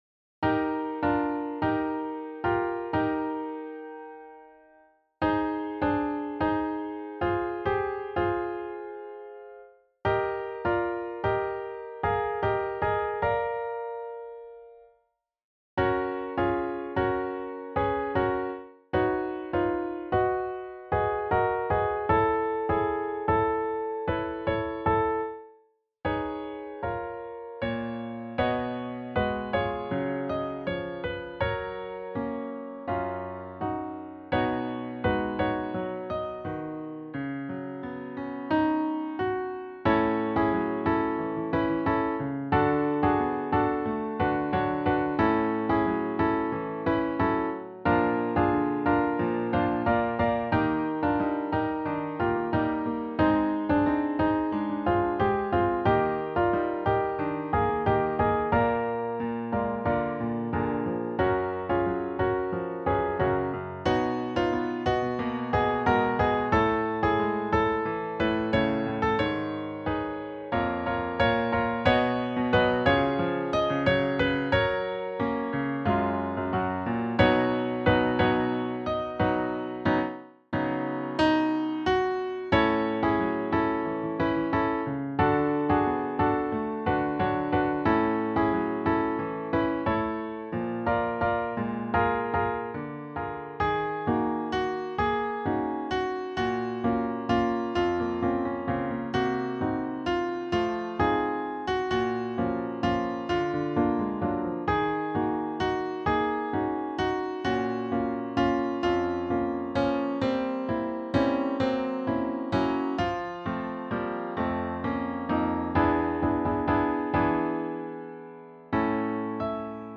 A Cappella Arrangement
Sample Audio (MIDI):